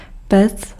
Ääntäminen
Ääntäminen US GenAm: IPA : /ˈfɝnɪs/ RP : IPA : /ˈfɜːnɪs/ Haettu sana löytyi näillä lähdekielillä: englanti Käännös Ääninäyte Substantiivit 1. pec {f} Määritelmät Substantiivit A device for heating.